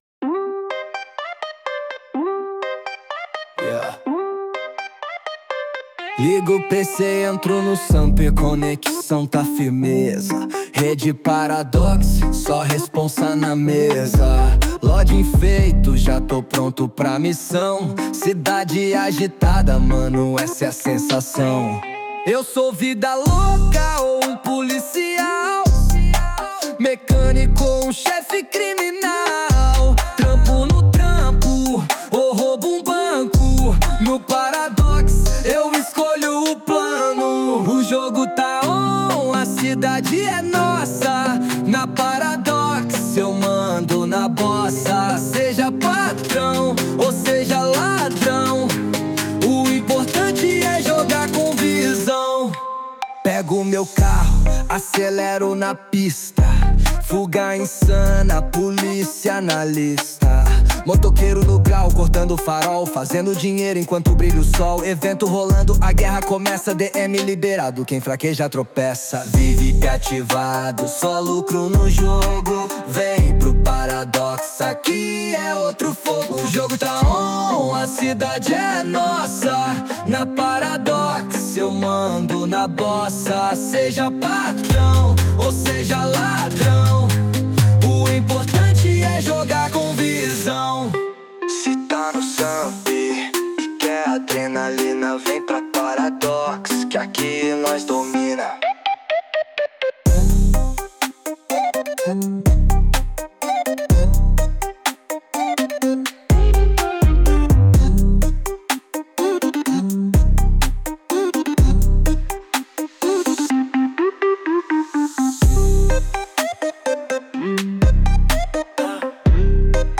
2025-02-08 12:05:38 Gênero: Trap Views